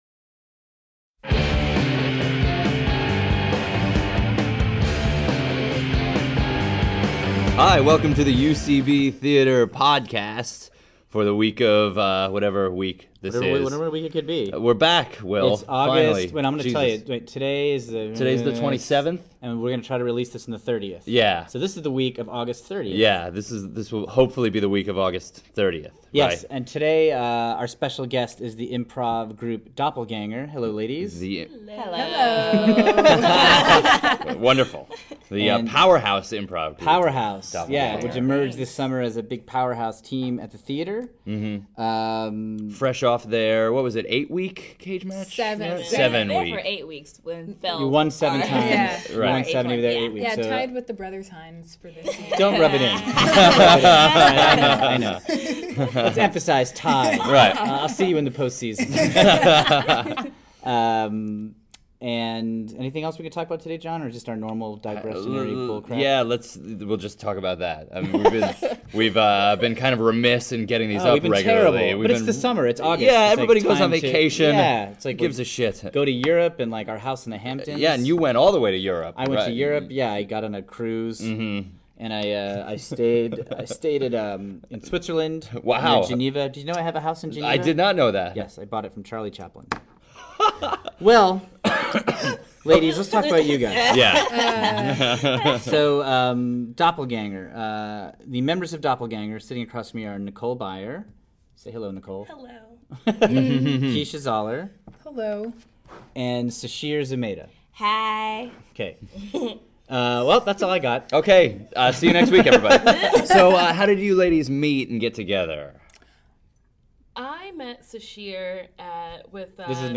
We interview the new improv power trio Doppelganger!